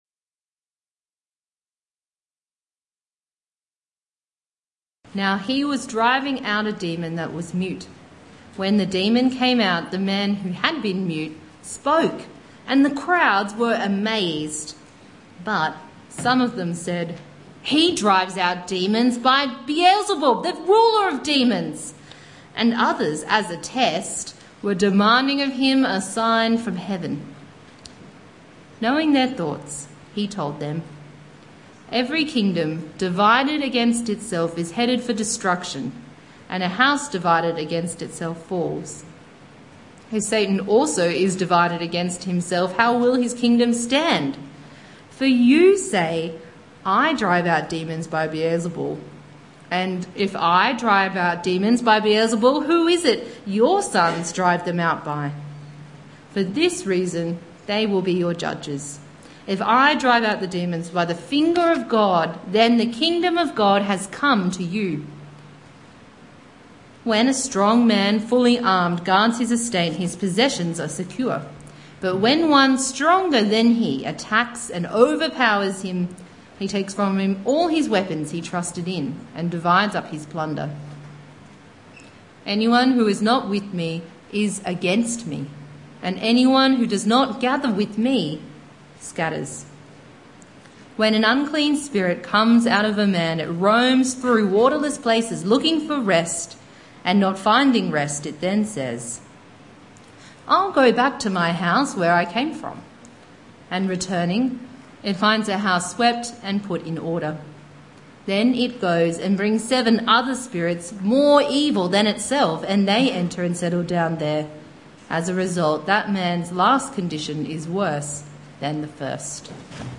Evening Church